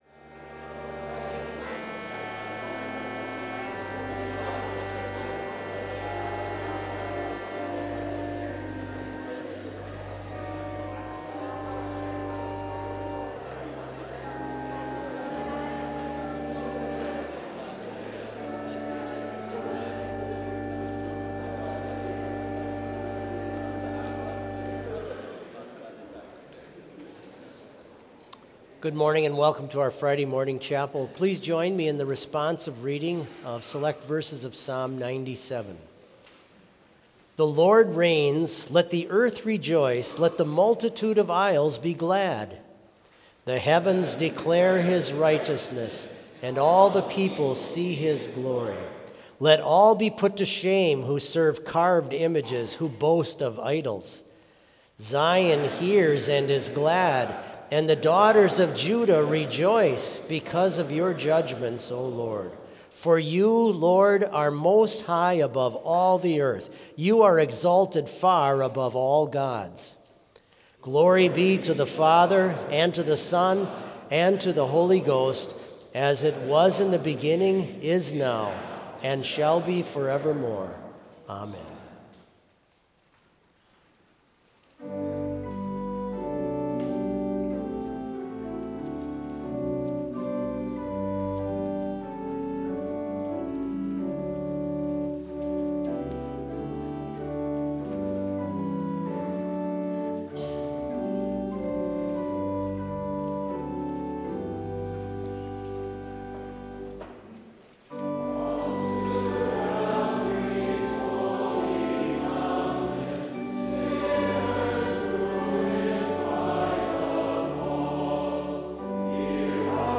Complete service audio for Chapel - Friday, January 31, 2025